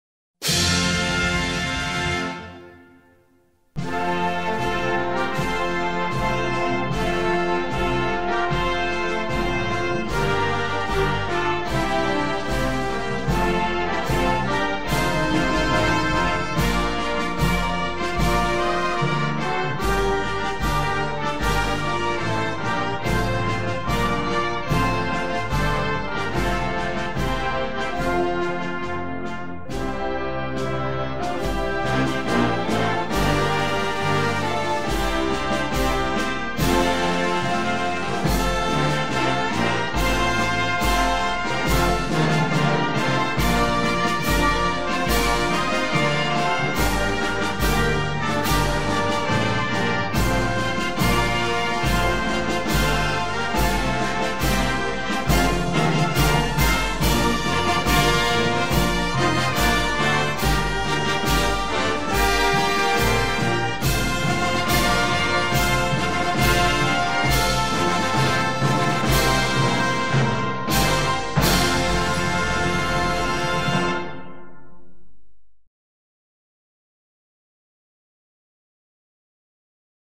инструментальная версия без слов